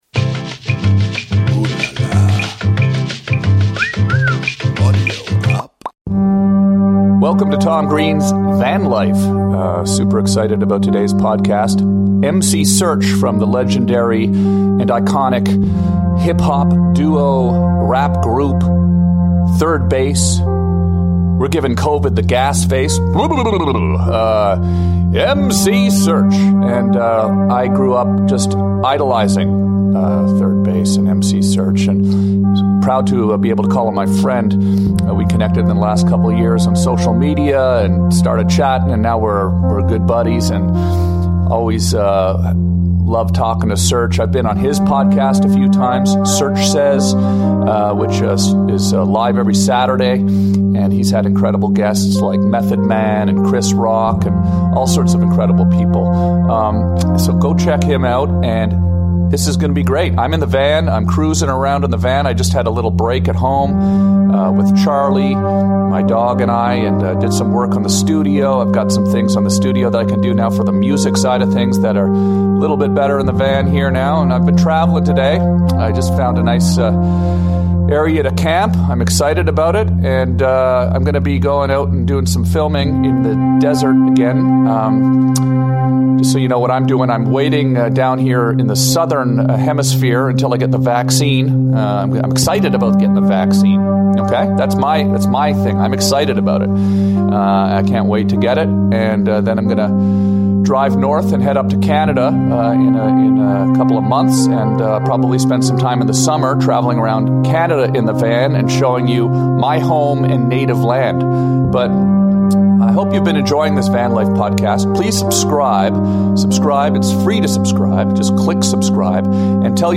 In his customized, state-of-the-art broadcast van, Tom takes us on an audio-driven ride speaking to van lifers, influencers, outdoor experts, scientists, artists, celebrities and real (and un-real) people he encounters along the way. If there ever were a podcast to embrace the nomad culture this is it — part interview show, part travel show, part outdoor adventure.